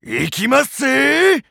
Dribble's voice from the official Japanese site for WarioWare: Move It!
WWMI_JP_Site_Dribble_Voice.wav